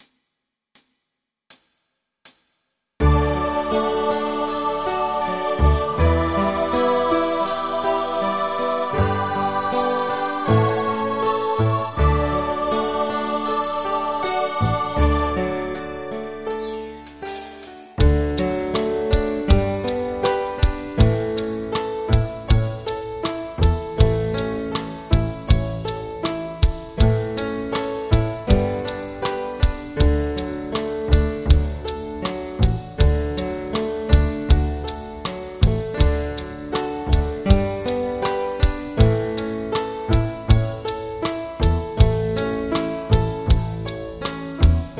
MIDI · Karaoke